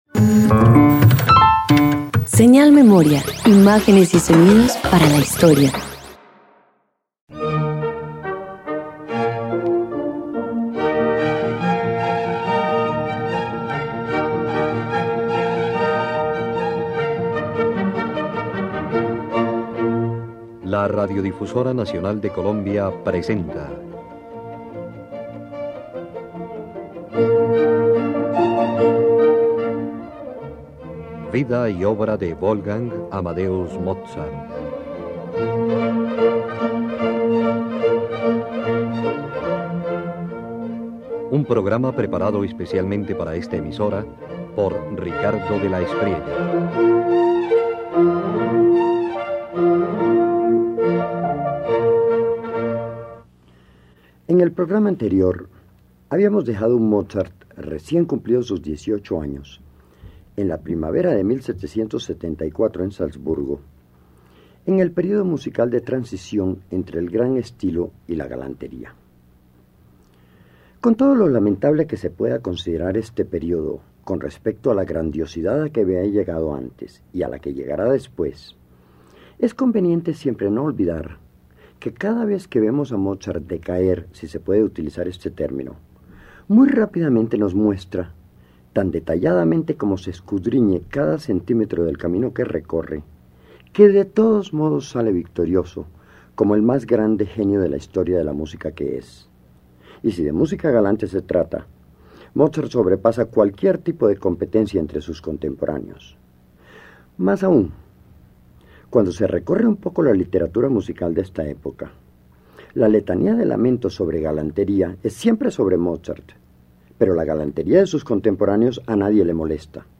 Mozart experimenta con el sonido orquestal: el Concertone en do mayor convierte a violines y vientos en dialogantes protagonistas, mientras el Concierto para fagot revela su ingenio para dotar un instrumento grave de elegancia y humor.
094 Concertone en Do Mayor  para  2  violines y Orquesta y  Concierto para Fagot y Orquesta_1.mp3